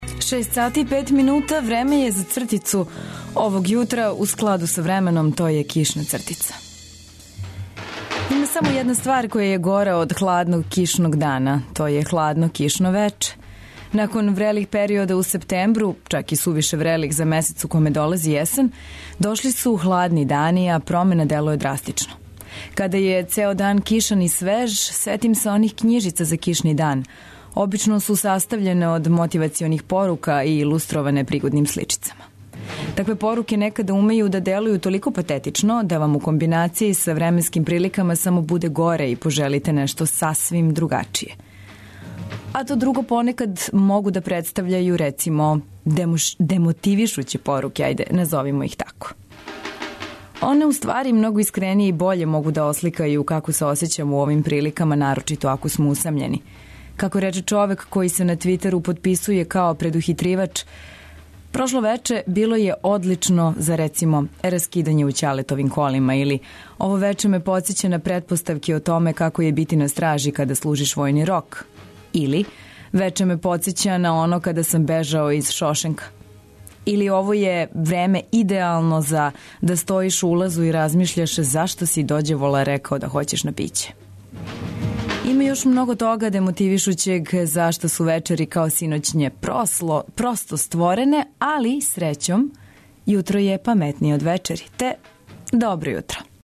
Уз добру музику, информације које је важно знати изјутра, најновије вести и различите радијске прилоге, желимо вам ДОБРО ЈУТРО!